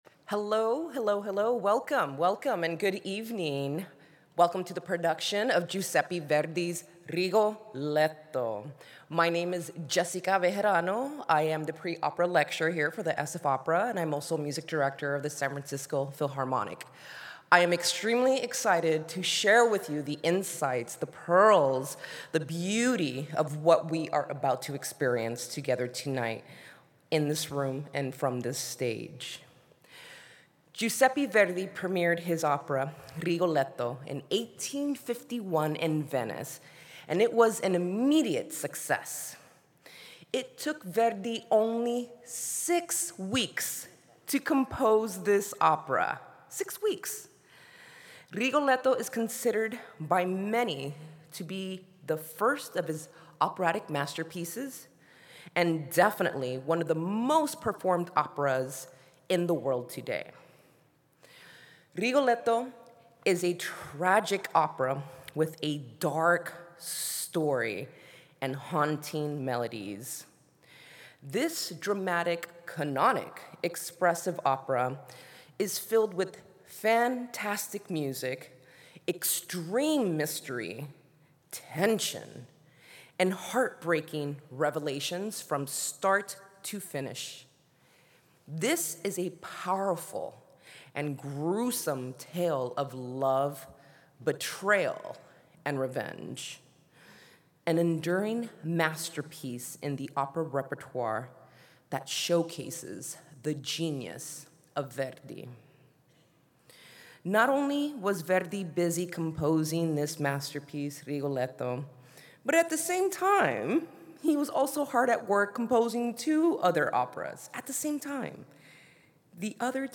rigoletto_pre-show_lecture.mp3